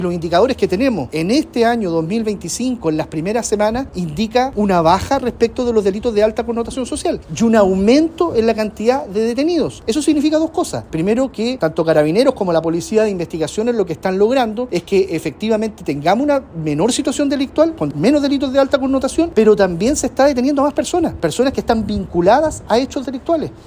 En este sentido, el delegado presidencial de la región del Bío Bío, Eduardo Pacheco, condenó el actuar de los sujetos y dijo que los delitos de alta connotación habían disminuido en la región.